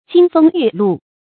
金風玉露 注音： ㄐㄧㄣ ㄈㄥ ㄧㄩˋ ㄌㄨˋ 讀音讀法： 意思解釋： 泛指秋天的景物。